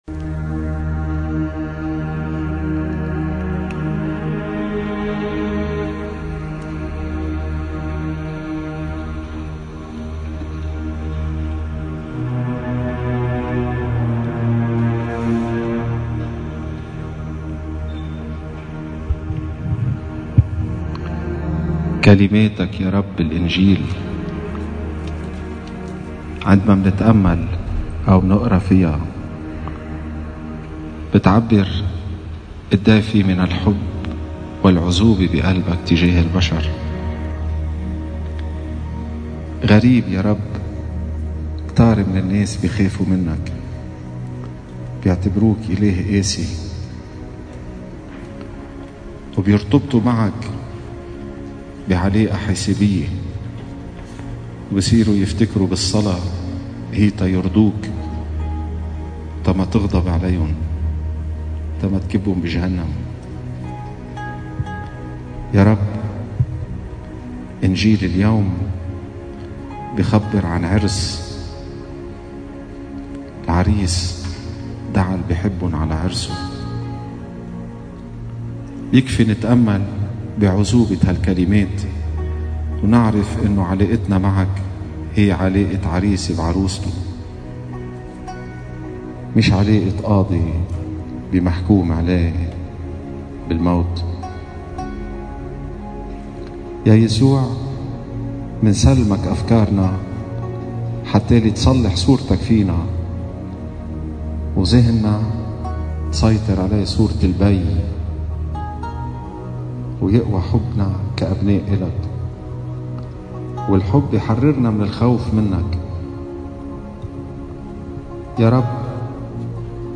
سجود أمام القربان المقدس